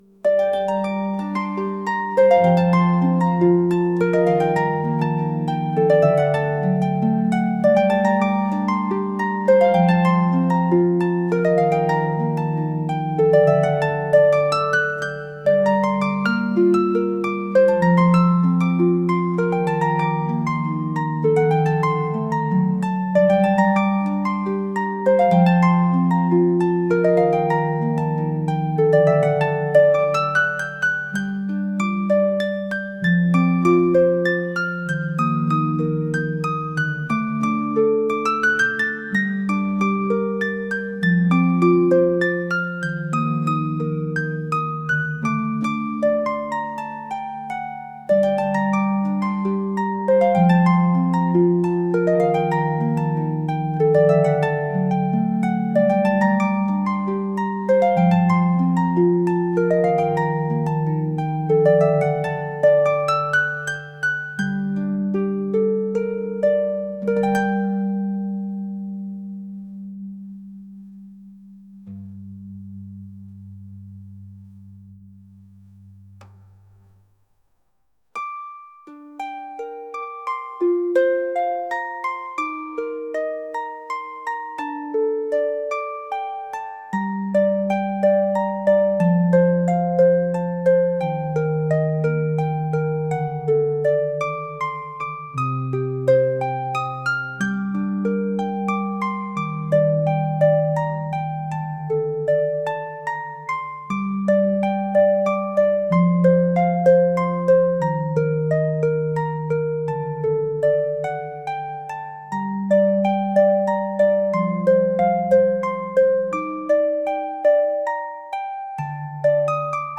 Calm Music